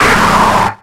Cri de Snubbull dans Pokémon X et Y.